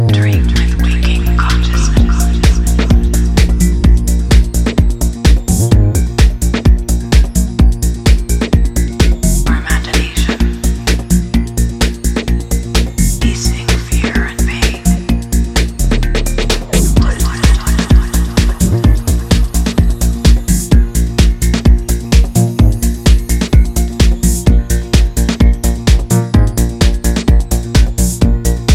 From hypnotic rhythms to enveloping melodies